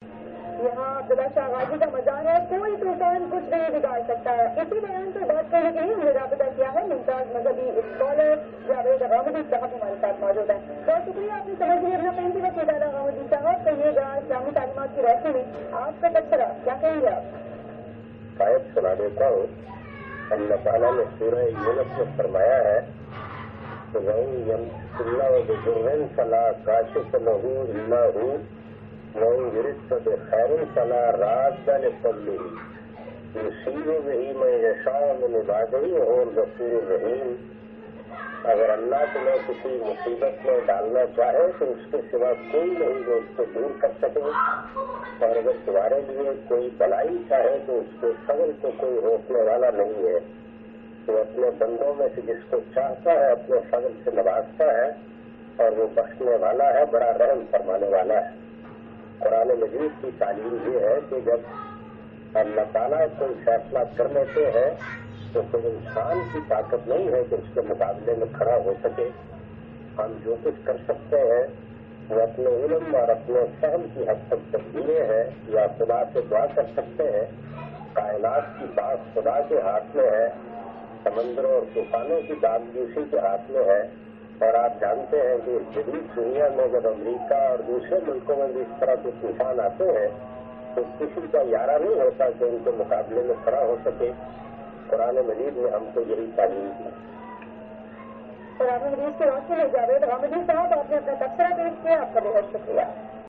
Javed Ahmad Ghamidi expressing his views regarding "Strom Nelofar and Shrines of Sufi Saints" in news bulletin on Samaa Tv.